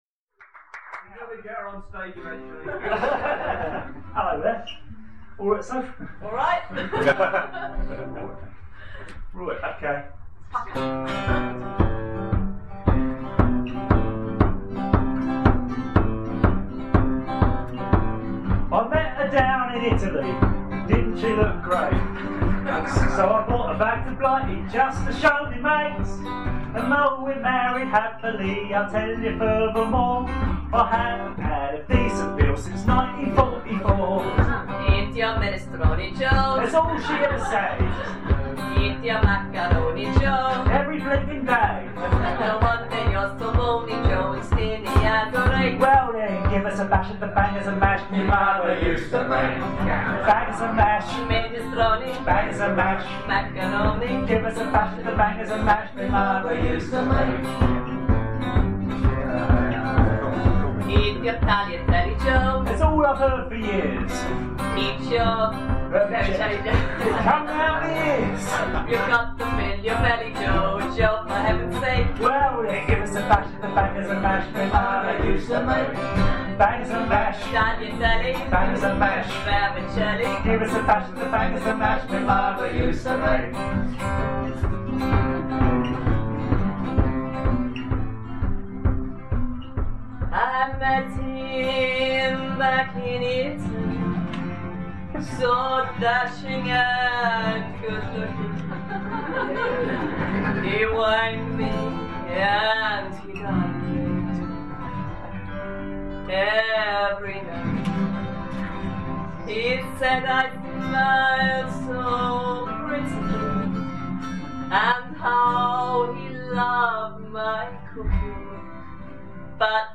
impersonation at a folk club comedy night